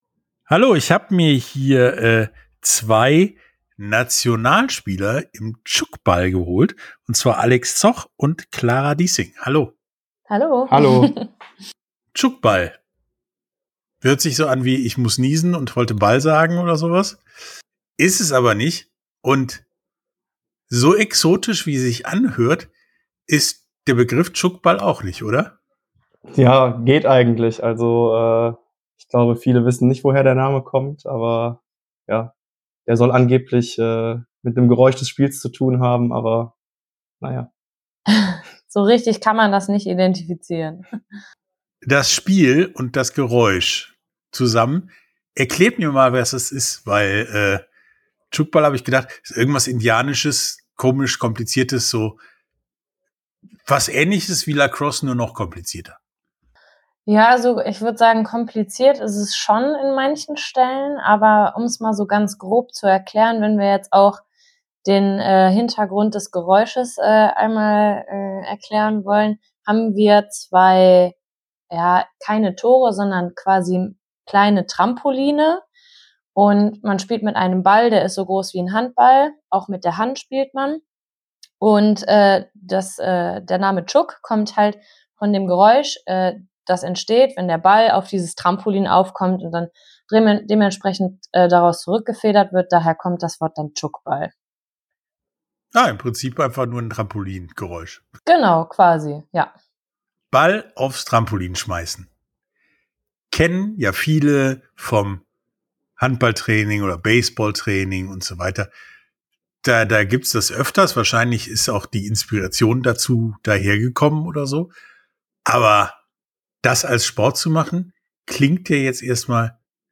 Sportstunde - Interview tchoukball komplett ~ Sportstunde - Interviews in voller Länge Podcast
tchoukball_komplett.mp3